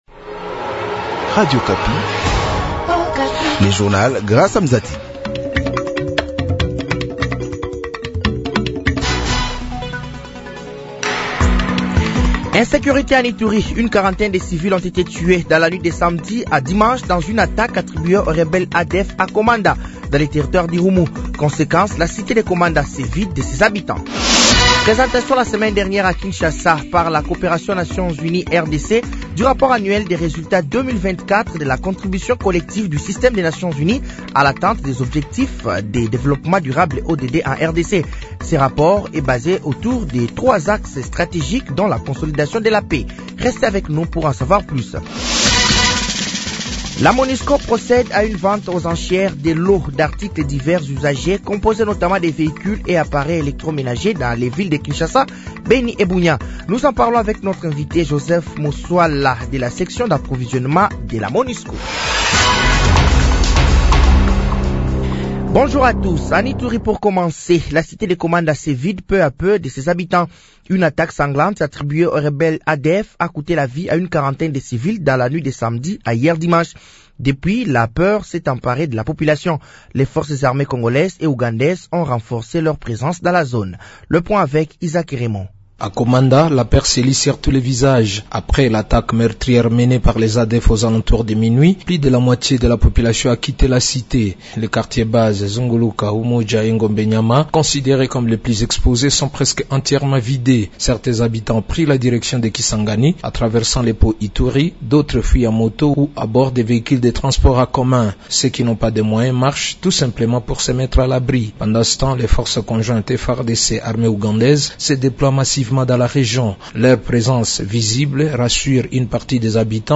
Journal français de 8h de ce lundi 28 juillet 2025